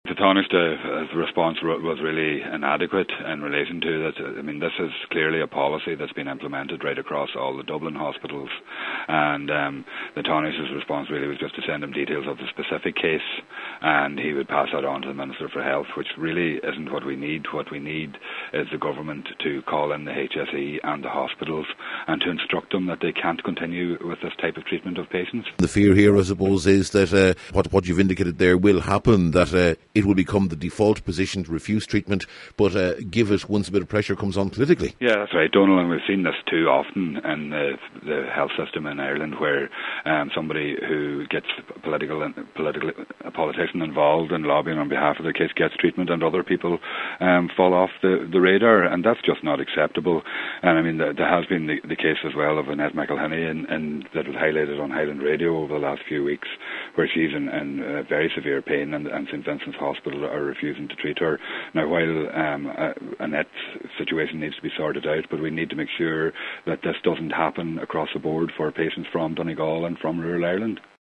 Tanaiste Eamon Gilmore suggested that details of the case be passed onto the Health Minister, but Deputy Pringle says that’s not good enough………..